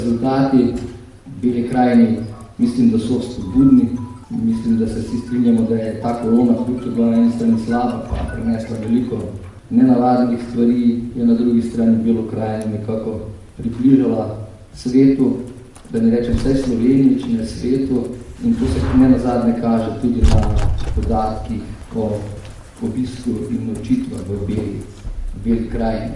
Črnomaljski župan Andrej Kavšek o kazalcih belokranjskega turizma